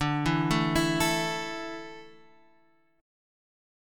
A5/D chord